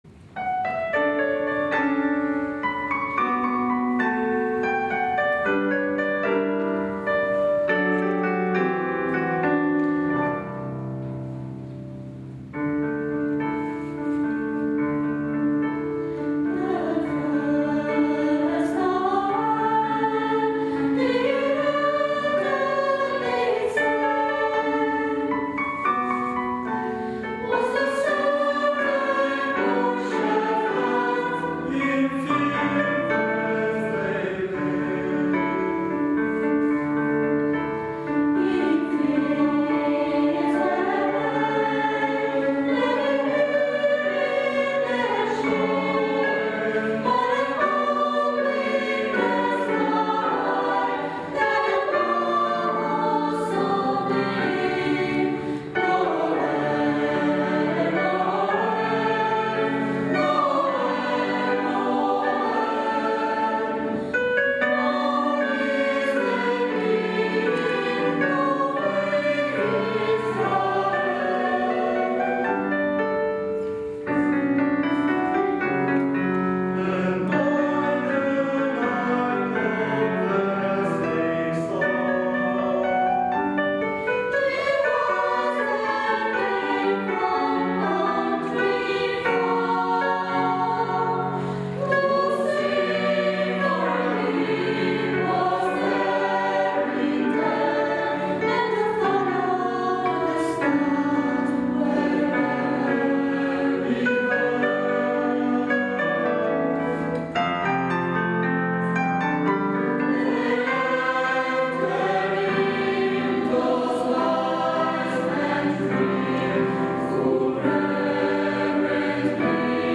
Natalizio Christmas carols Phillip Keveren